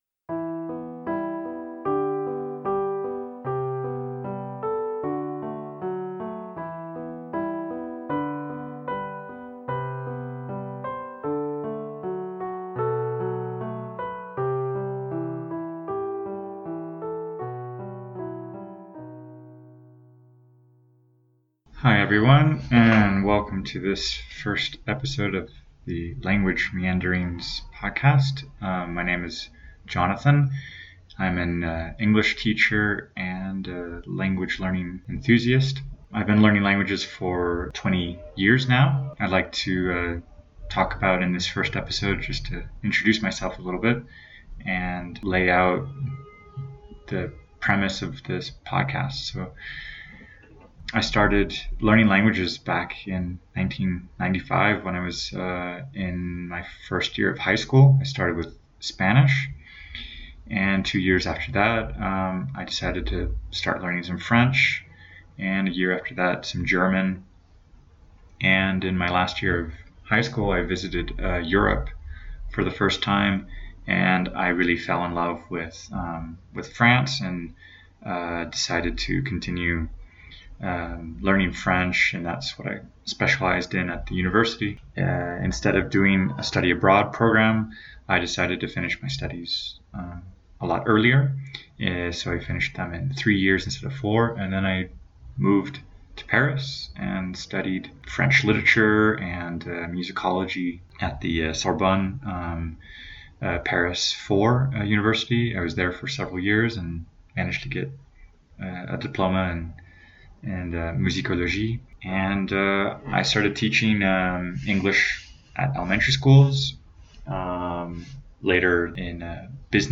Original music